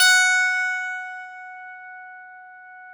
53s-pno15-F3.aif